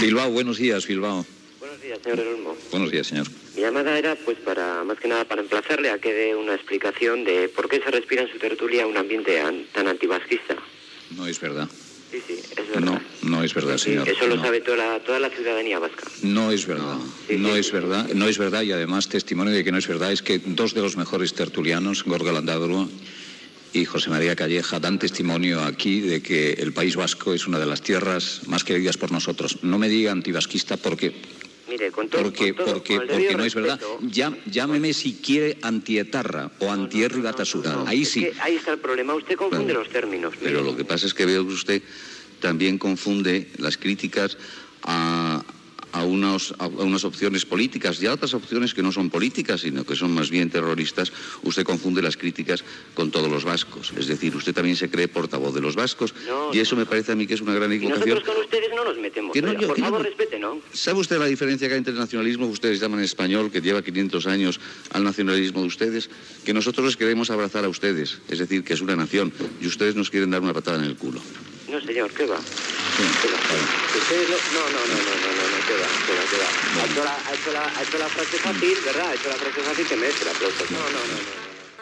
Trucada d'un oient basc que es queixa de l'ambient anti basc del programa.
Info-entreteniment